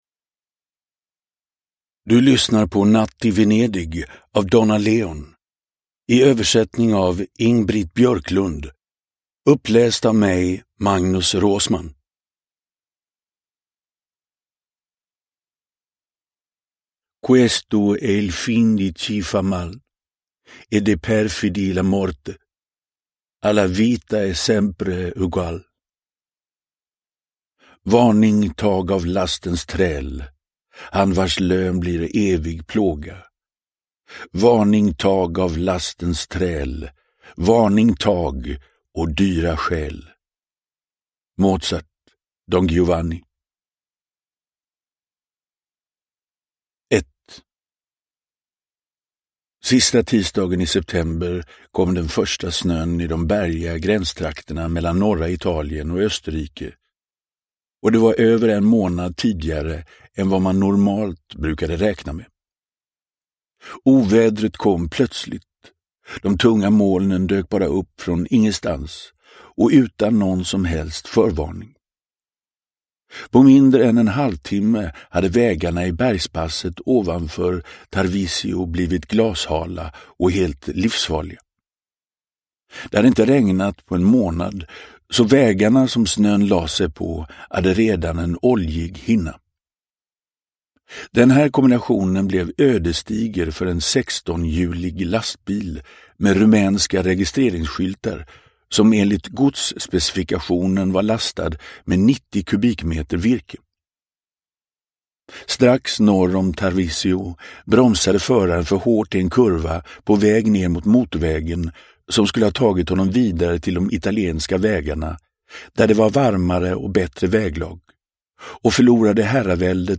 Natt i Venedig – Ljudbok – Laddas ner